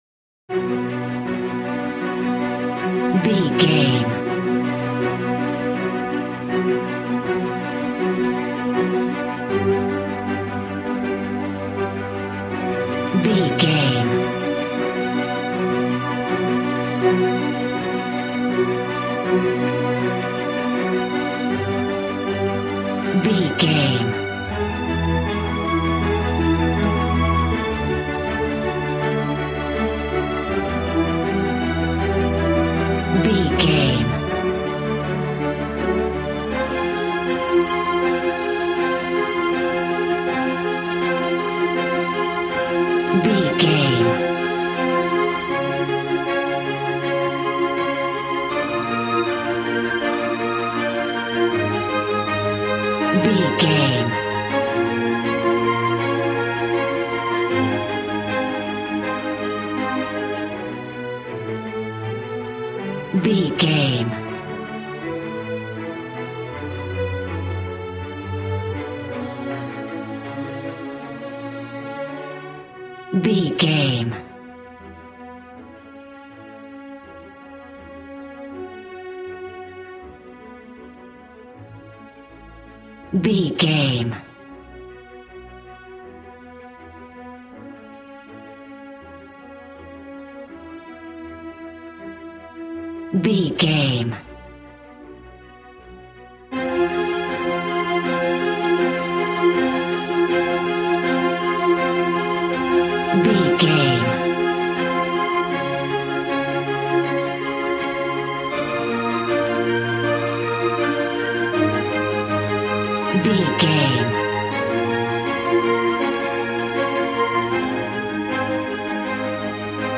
Aeolian/Minor
regal
cello
violin
strings